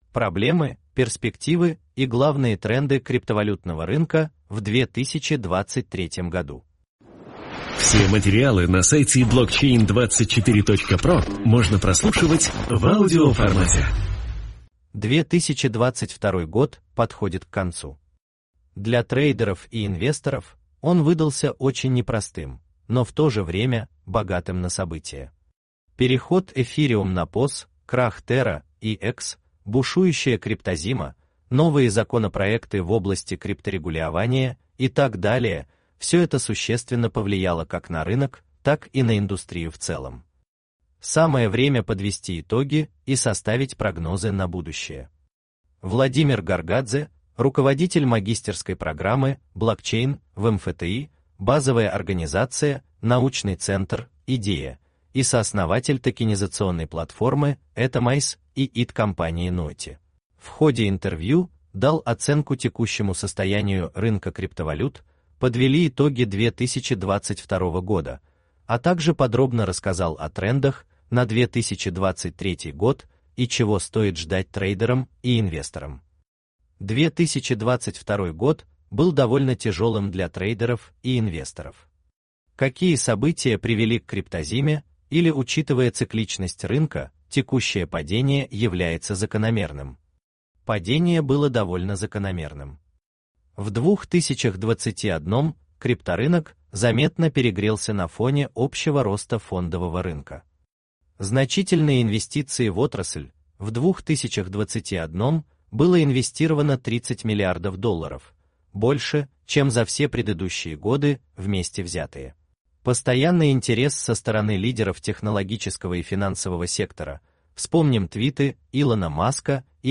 Берёт интервью